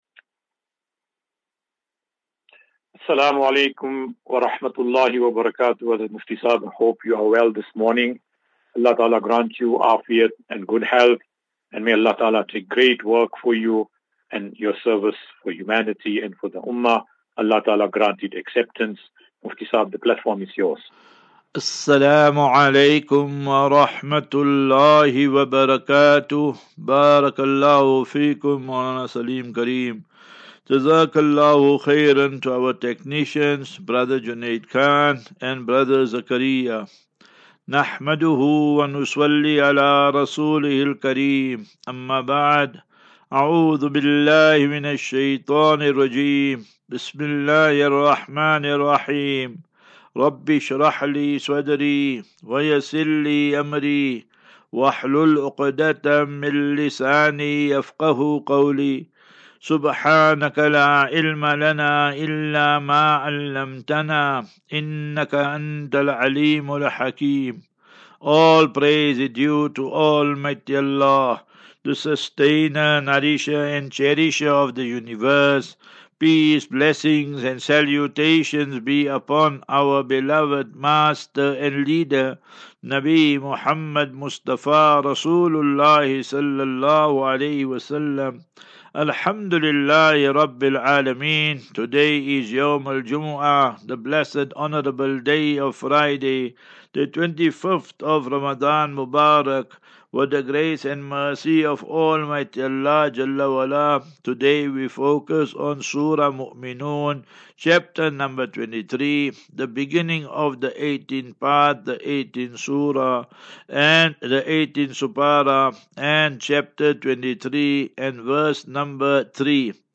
As Safinatu Ilal Jannah Naseeha and Q and A 5 Apr 05 April 2024.